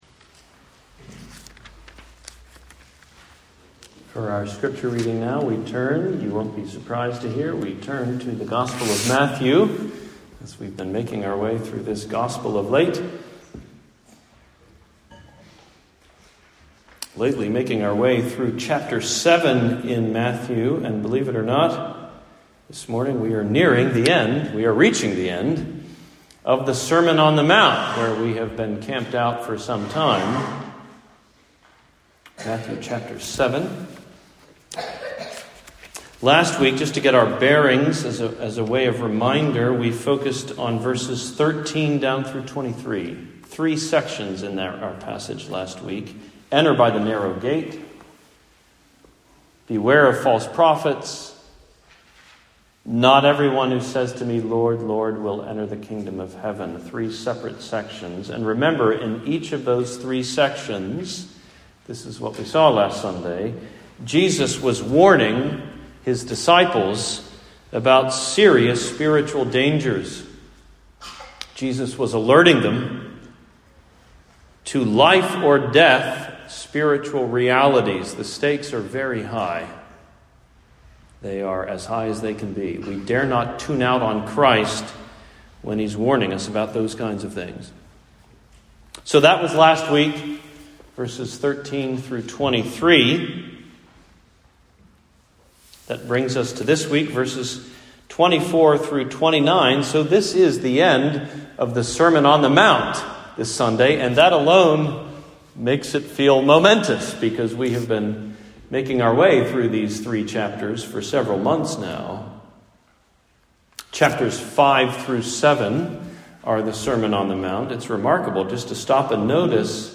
A Teacher Like No Other: Sermon on Matthew 7:24-29